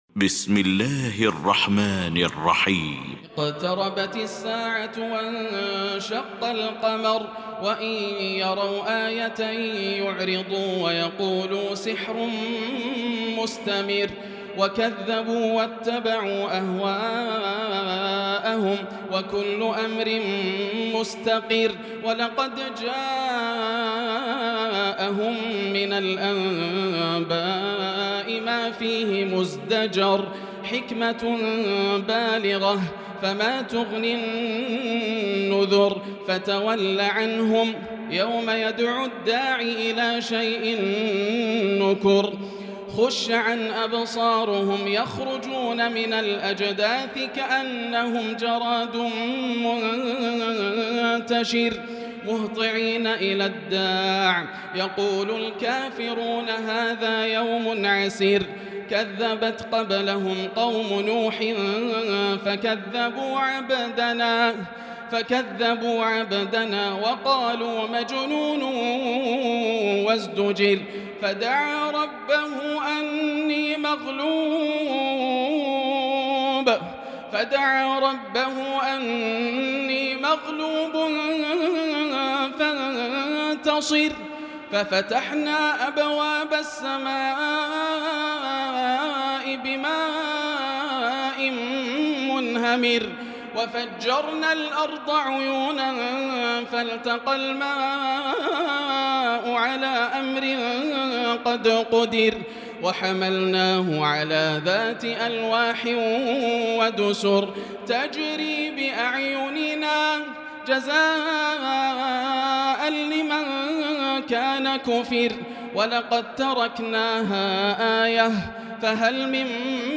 المكان: المسجد الحرام الشيخ: فضيلة الشيخ ياسر الدوسري فضيلة الشيخ ياسر الدوسري القمر